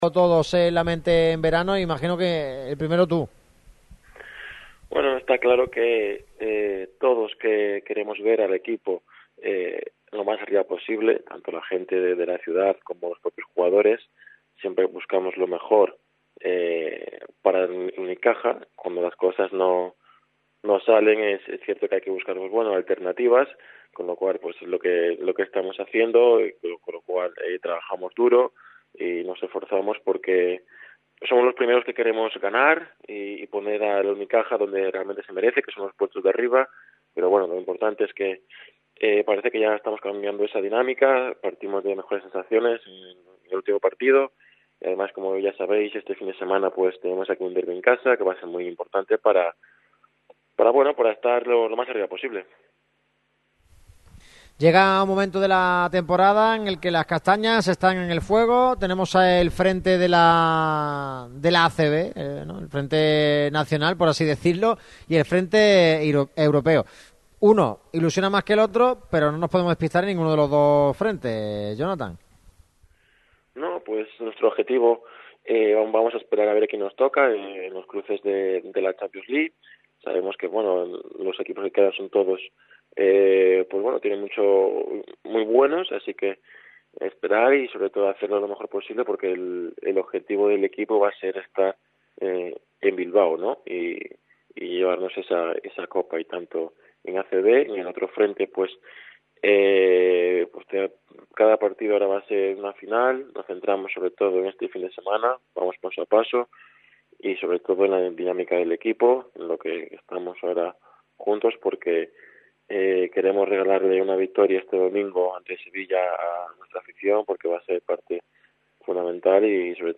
Nueva entrega de ‘Marca Basket, 40 años en verde y morado’, patrocinada por la Fundación Unicaja. Este jueves hemos charlado con Jonathan Barreiro, jugador destinado a liderar el futuro del Unicaja, y uno de los grandes fichajes del pasado verano en Los Guindos.